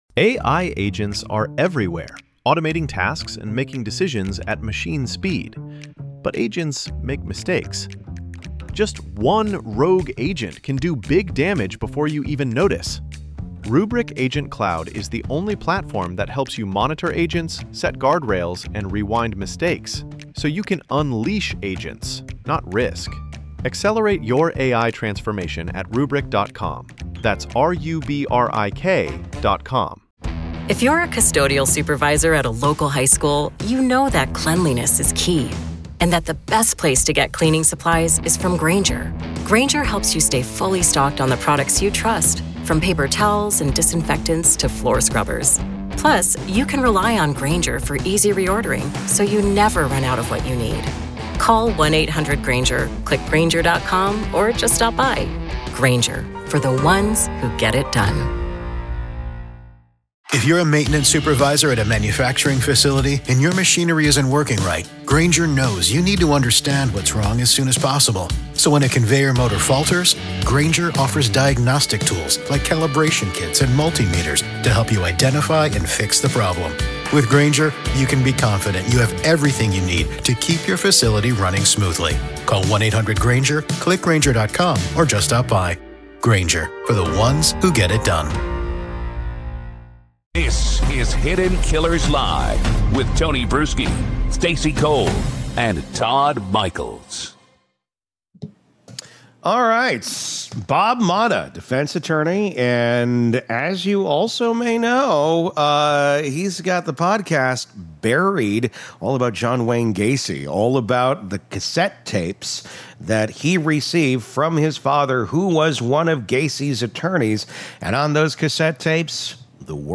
In this conversation